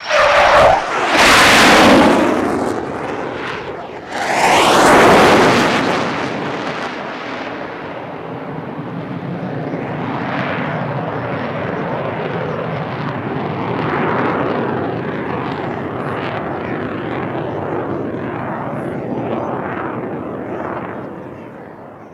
F-104 Pass By Left Mono